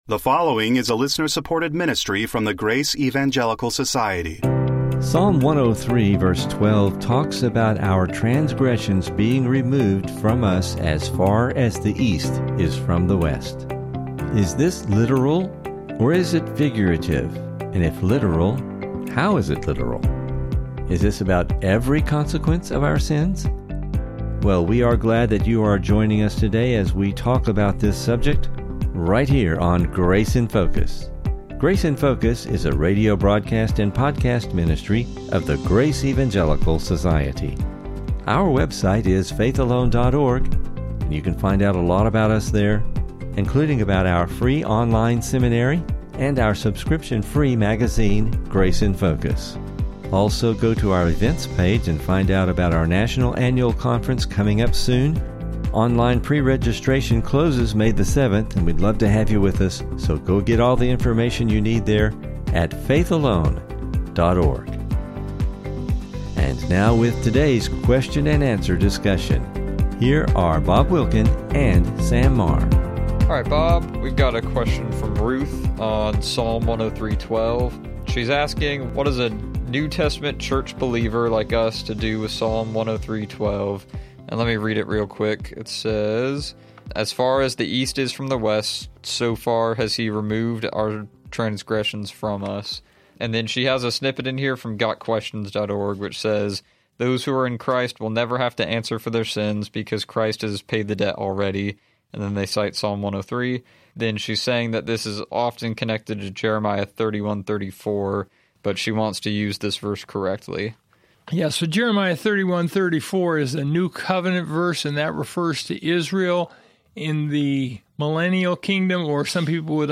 Is this about every consequence of our sins? Please listen for an informative discussion and never miss an episode of the Grace in Focus Podcast!